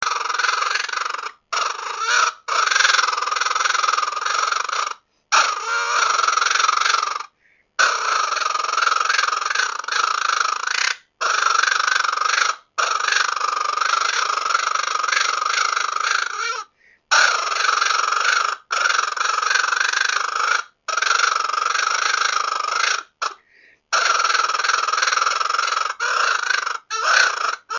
На этой странице собрана коллекция звуков, которые издают еноты.
Звуки енота: Звук, который может издать лишь малыш-енот